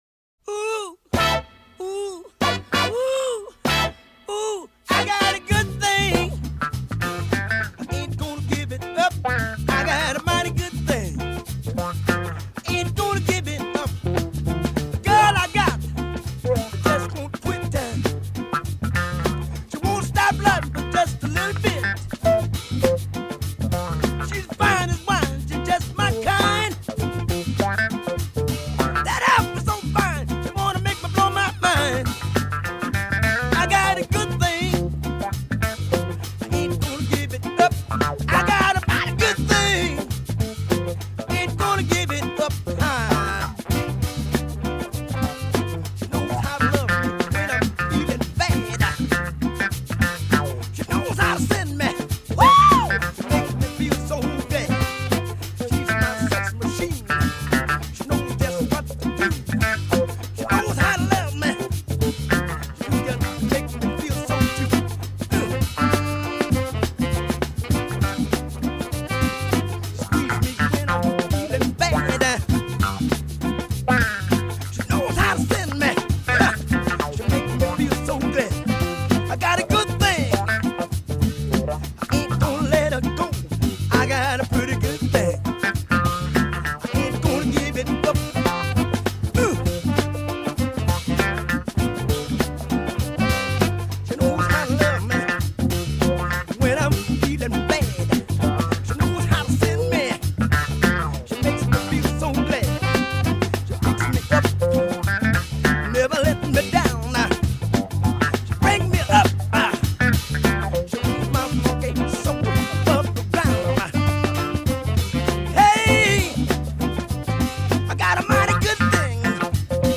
Genre : R&B.